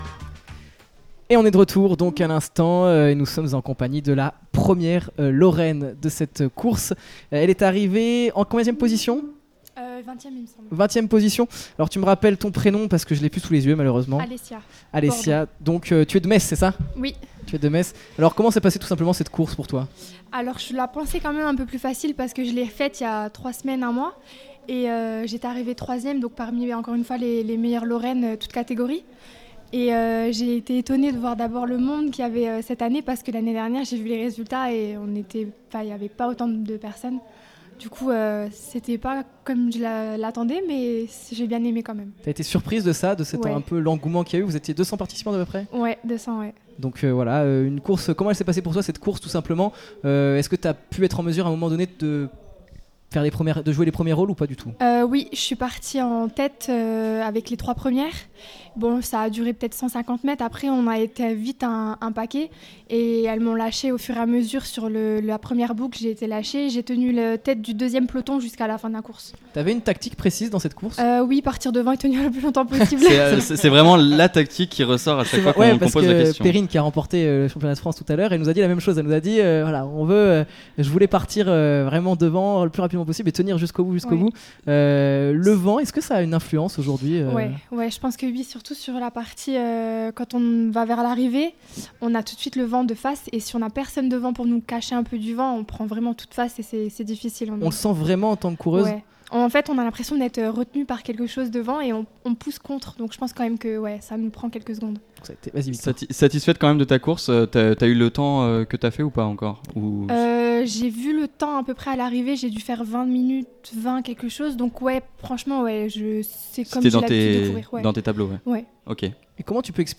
Es-tu Sport est parti couvrir la course au plus proche du terrain.